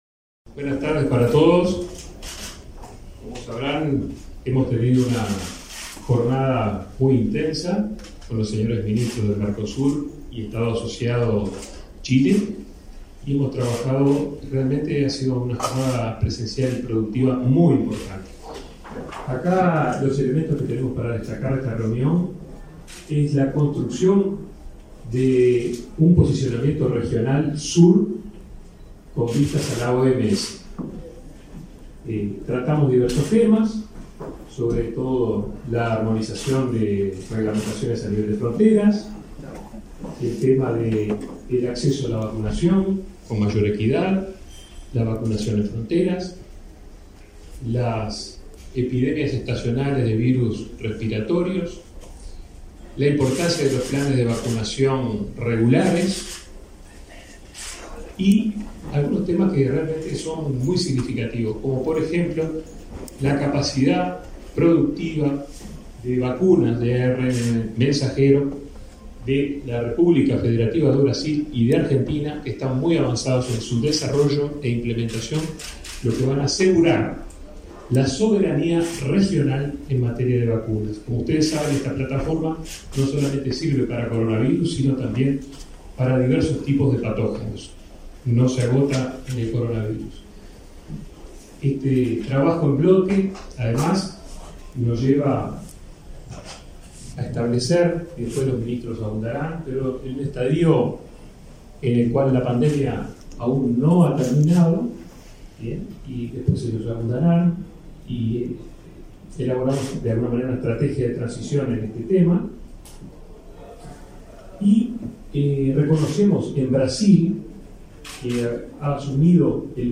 Palabras del ministro de Salud Pública, Daniel Salinas
Palabras del ministro de Salud Pública, Daniel Salinas 22/03/2022 Compartir Facebook X Copiar enlace WhatsApp LinkedIn Los ministros de Salud del Mercosur acordaron en Montevideo una posición conjunta respecto a temas sanitarios, tras reunirse este 22 de marzo. En el evento, se expresó el ministro Salinas.